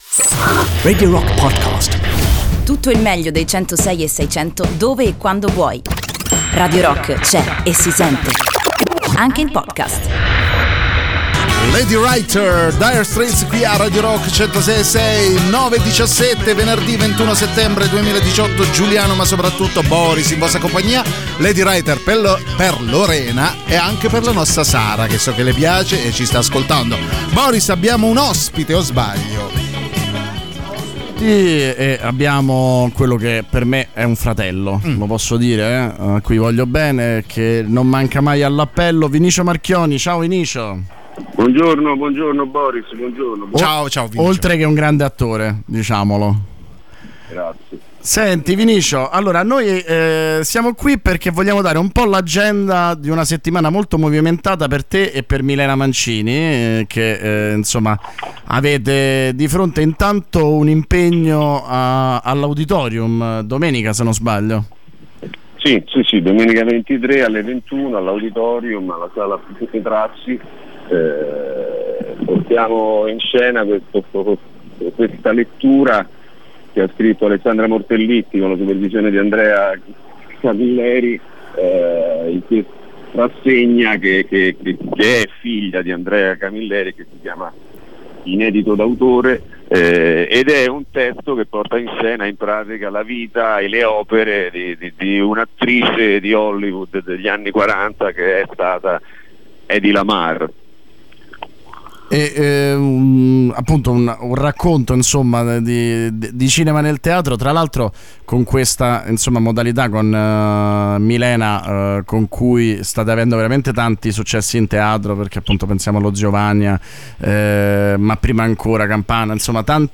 Intervista: Vinicio Marchionni (21-09-18)